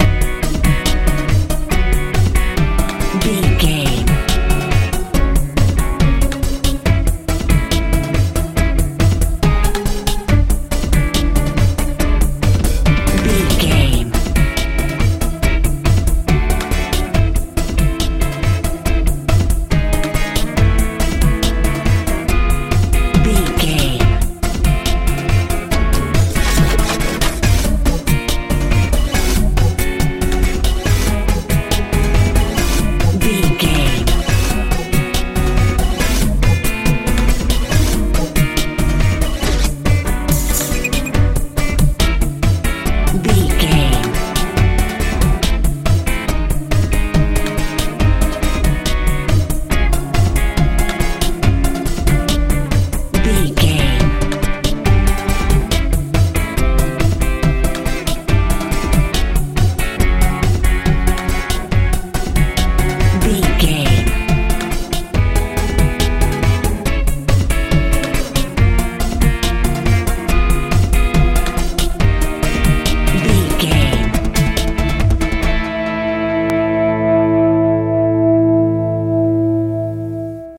modern dance
Ionian/Major
E♭
electric guitar
bass guitar
drums
synthesiser
80s
90s
strange